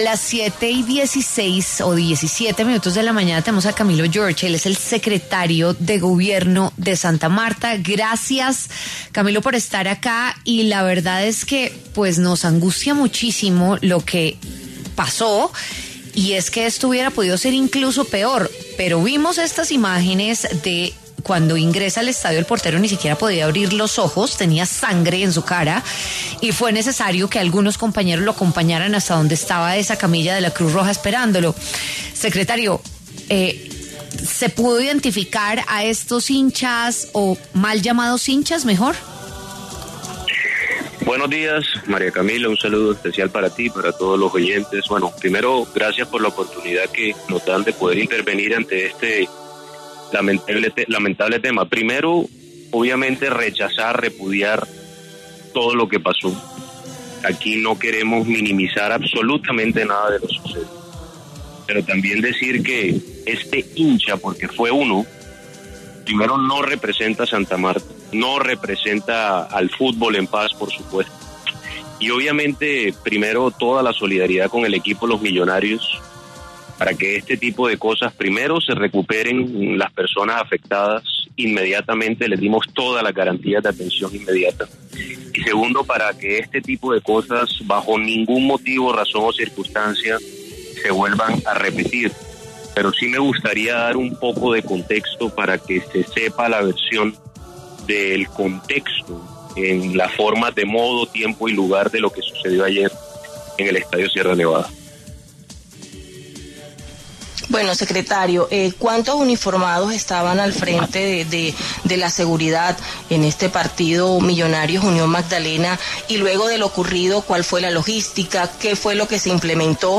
El secretario de Gobierno de Santa Marta, Camilo George, explicó en W Fin De Semana que fue una sola persona la que atacó al bus de Millonarios. Confirmó que no se tiene identificado al sujeto.